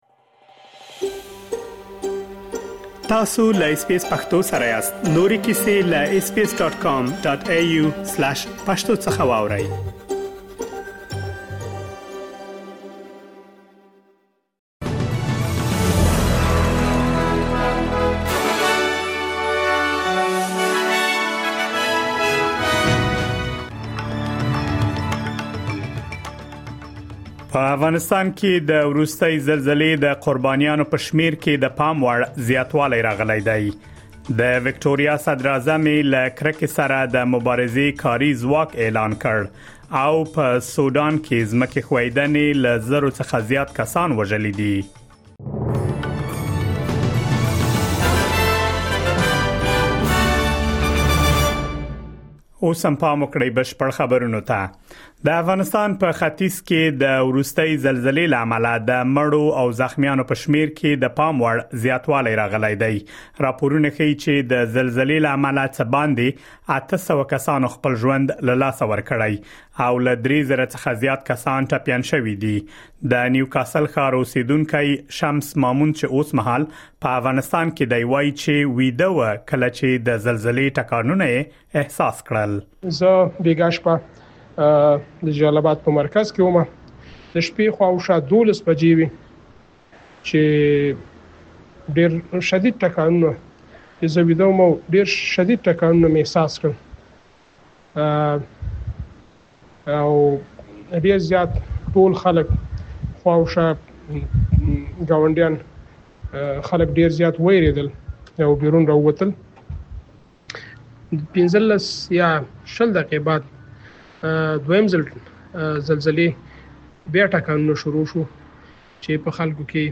د آسټراليا او نړۍ مهم خبرونه
د اس بي اس پښتو د نن ورځې لنډ خبرونه دلته واورئ.